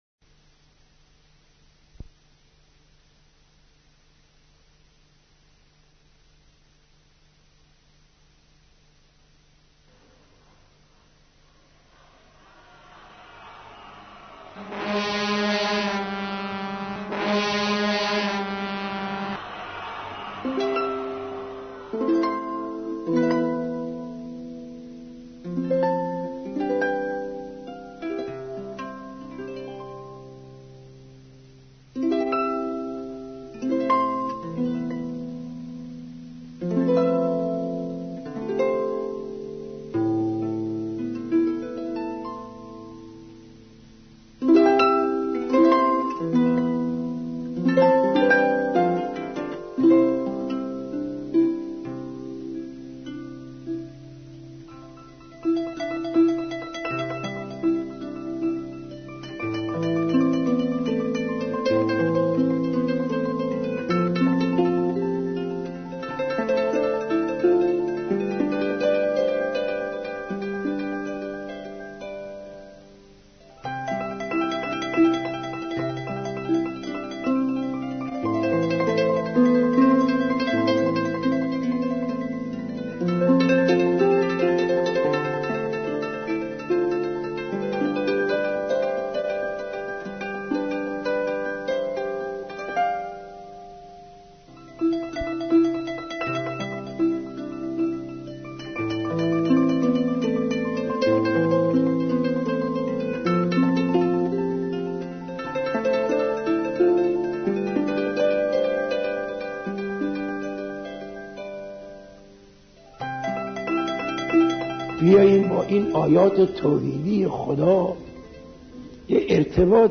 صوت(5) سخنرانی حضرت روحانی شهید حاج صادق احسان‌بخش